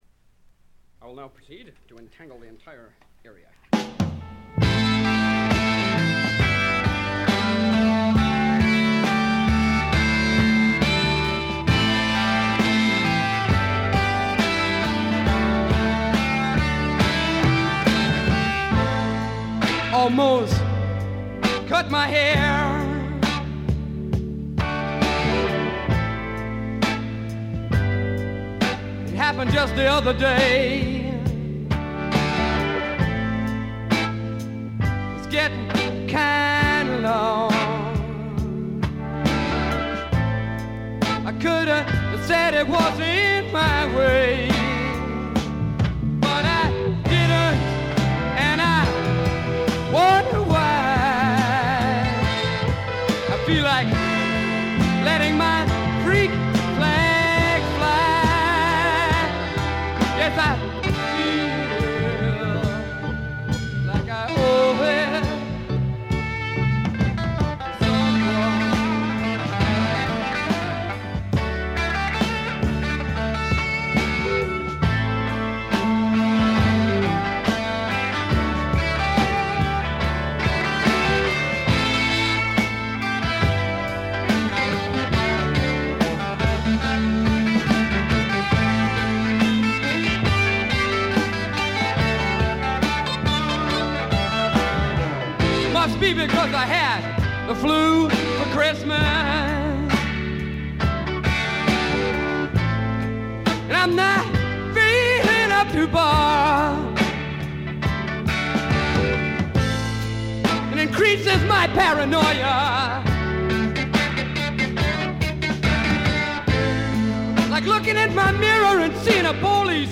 部分試聴ですが静音部での微細なバックグラウンドノイズ程度。
試聴曲は現品からの取り込み音源です。